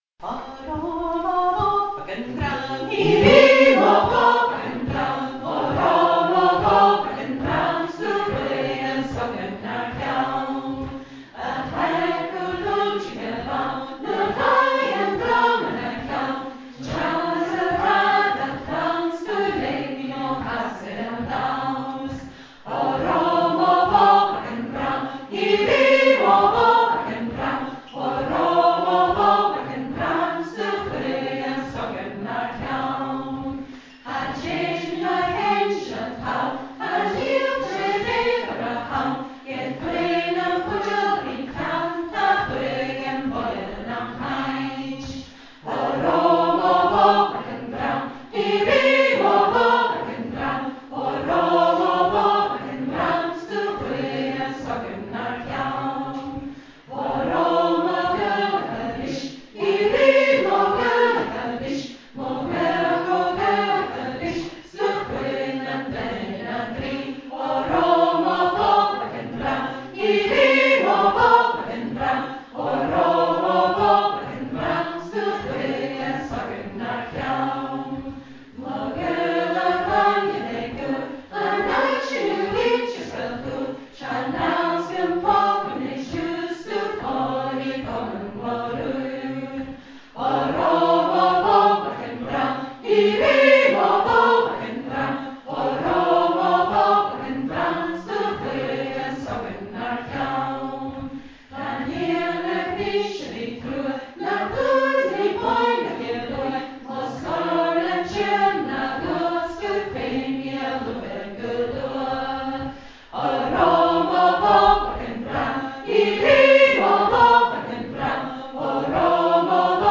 Here are recordings from the cèilidh on Thursday night when we sang a some of the songs we learnt: